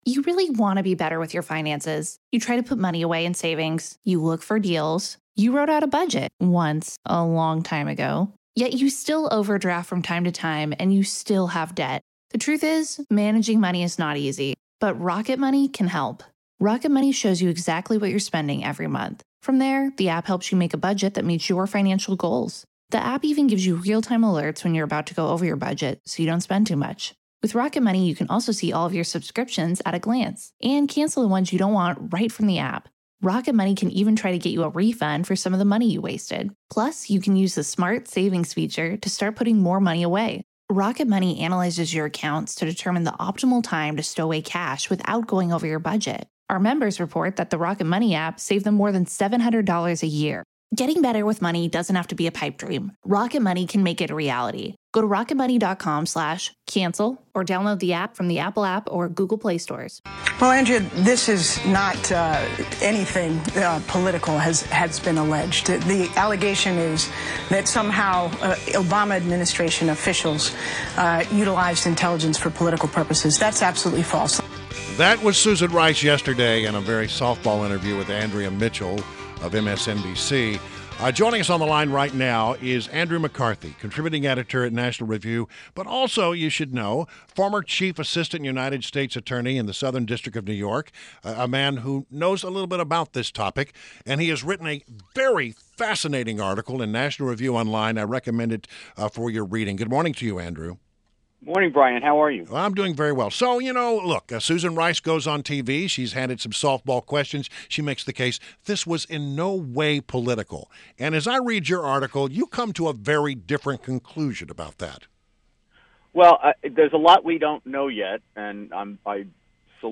WMAL Interview - ANDREW MCCARTHY - 04.05.17